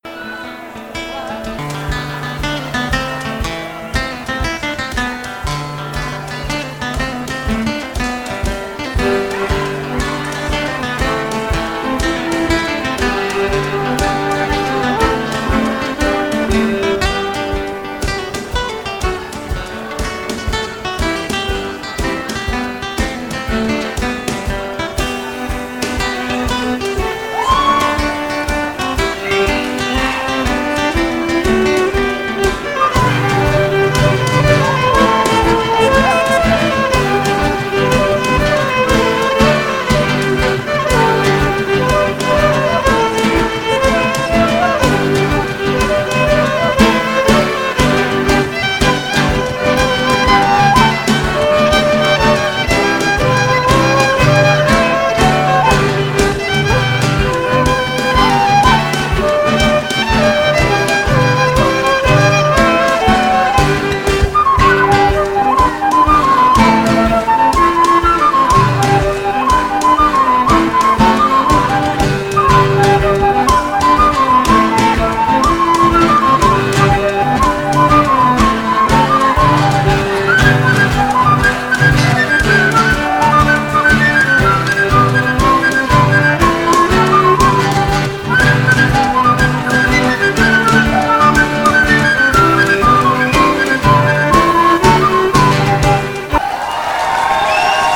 Jig
Voici un enregistrement du morceau en bal, joué par mon groupe Draft Company.